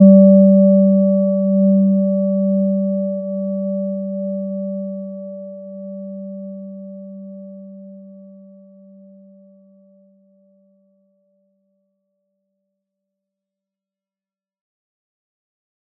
Gentle-Metallic-1-G3-mf.wav